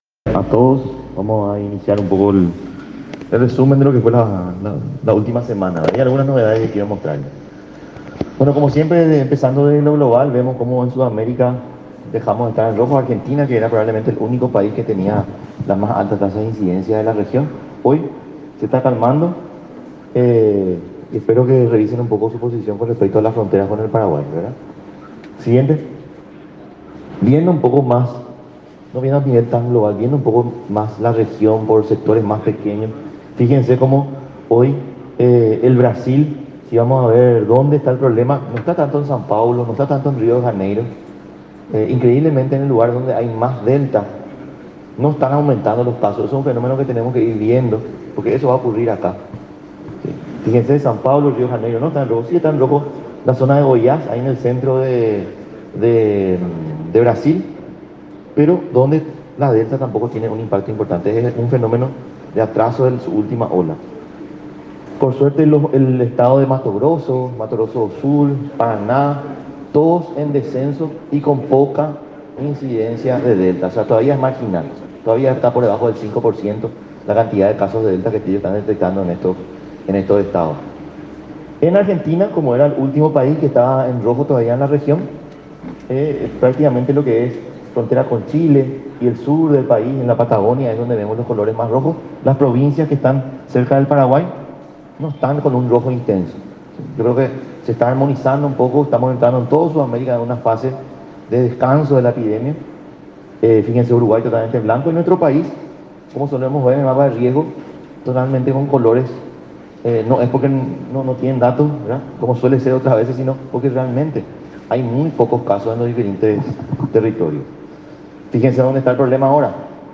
09-GUILLERMO-SEQUERA-CONFERENCIA-DE-PRENSA.mp3